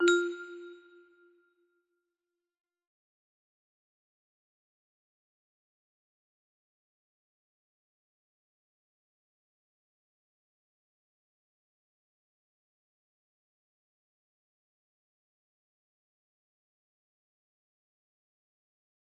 - music box melody